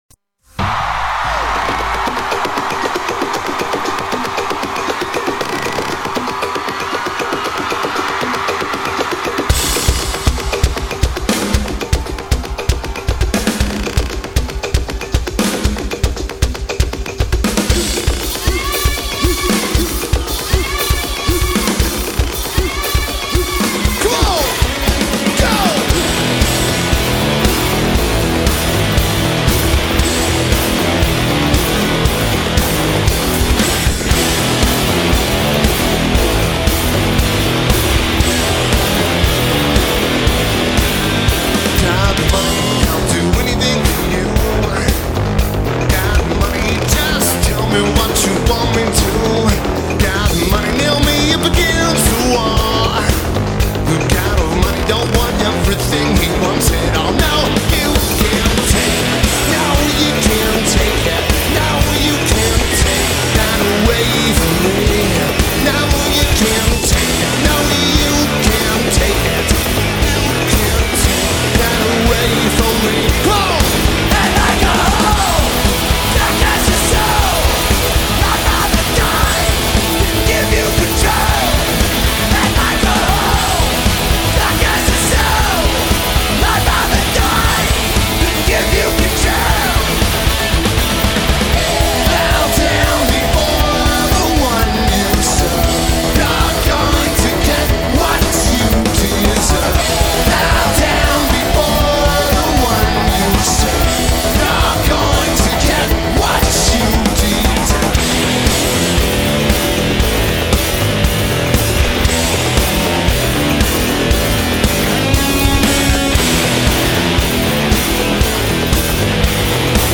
Etp Festival
Lineage: Audio - PRO (Soundboard Ripped from Pro-Shot Vid)
Soundboard audio.